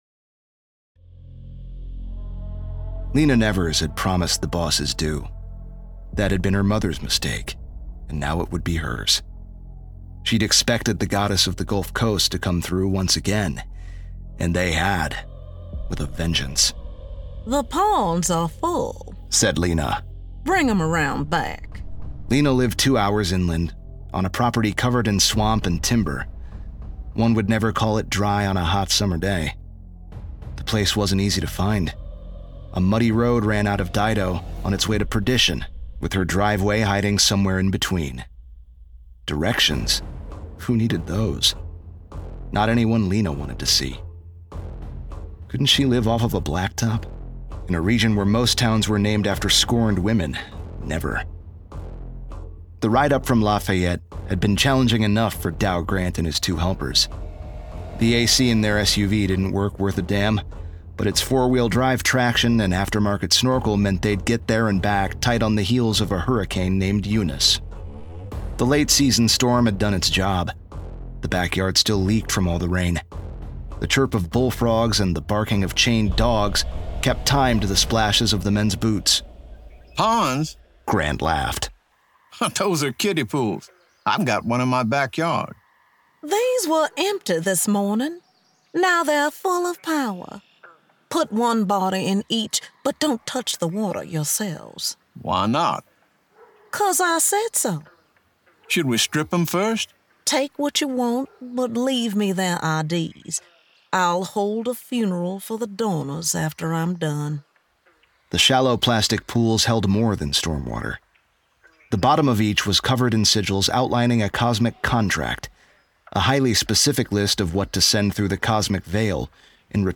• Audiobook • 10 hrs, 58 mins
SAINTSVILLE, the full-cast audiobook production: Human actors. Human voices. In a world that’s anything but…